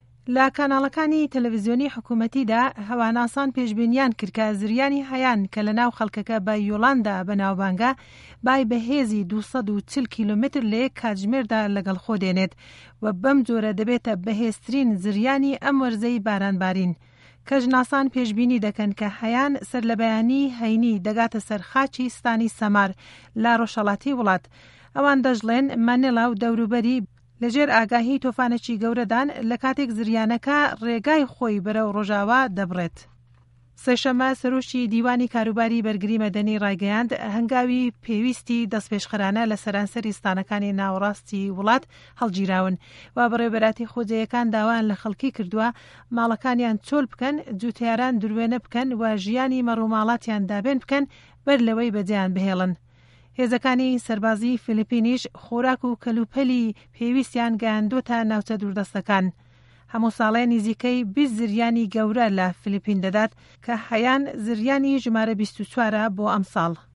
ڕاپـۆرتی زریانی فلـپـین